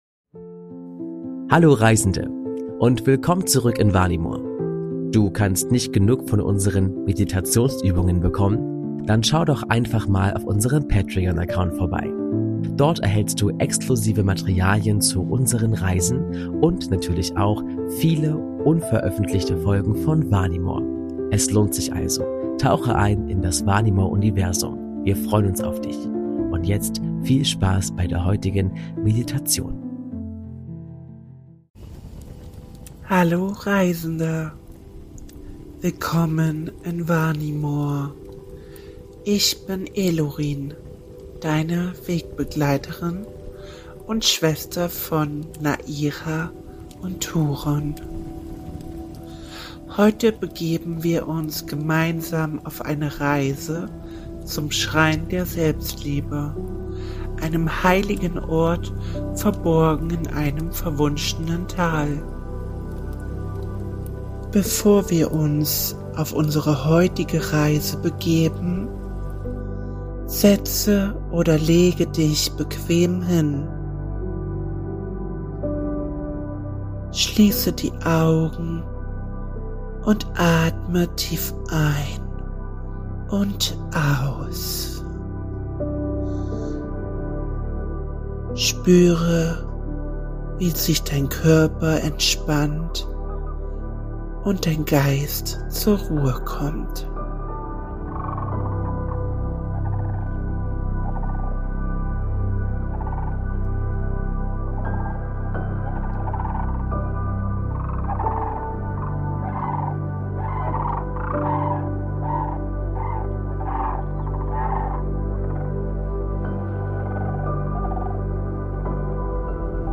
Entspannungsgeschichte: Elurin und der Schrein der Selbstliebe ~ Vanimor - Seele des Friedens Podcast